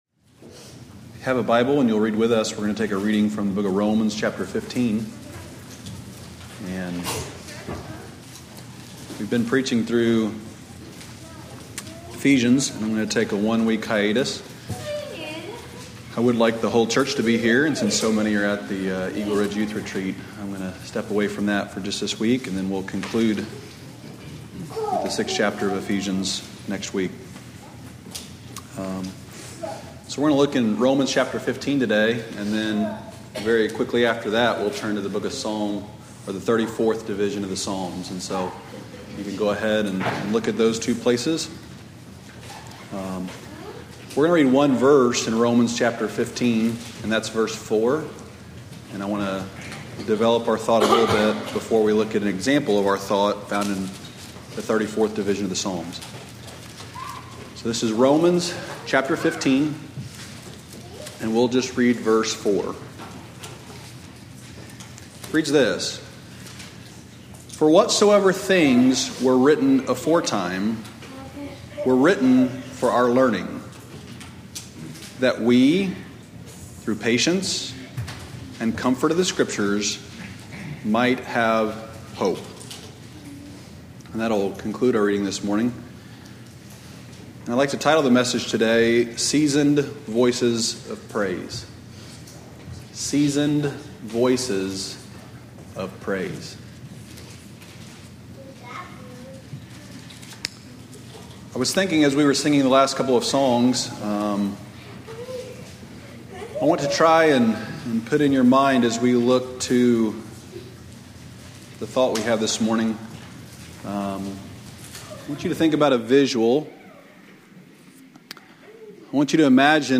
Lesson 1 from the 2009 Old Union Ministers School.